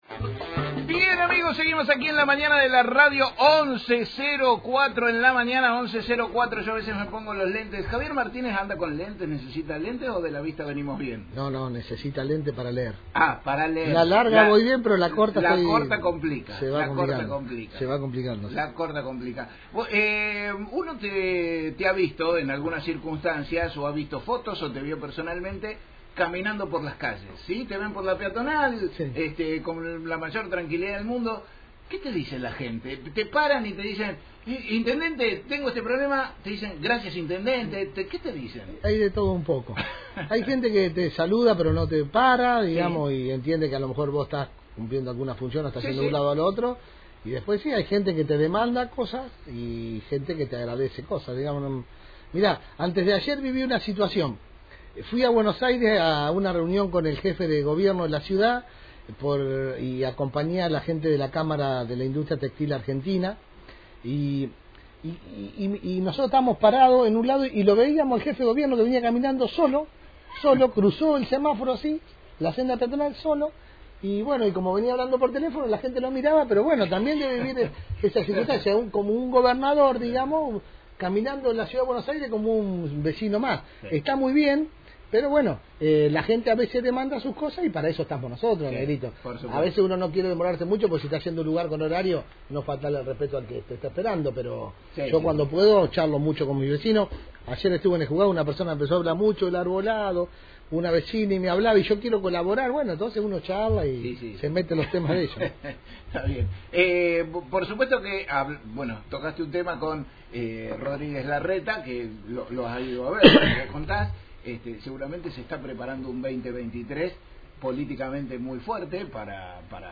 El intendente del Partido de Pergamino, el Dr Javier Martínez, se hizo presente en los estudios de AM1540, donde saludo a los vecinos en el aire de «La Mañana de la Radio».